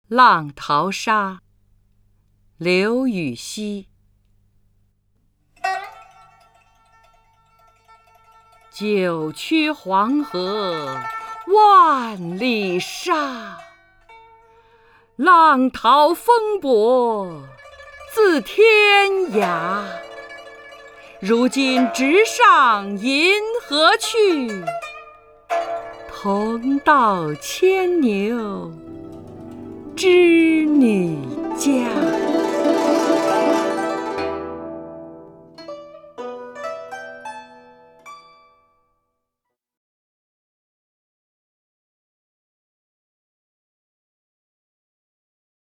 张筠英朗诵：《浪淘沙·九曲黄河万里沙》(（唐）刘禹锡)